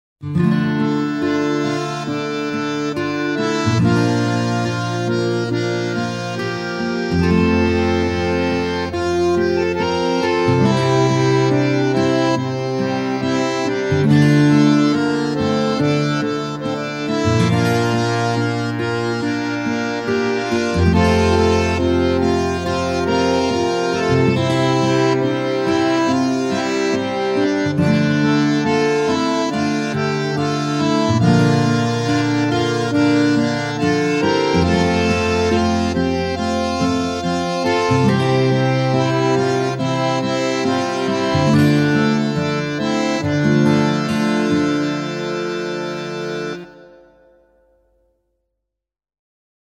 intimiste - folk - melodieux - romantique - aerien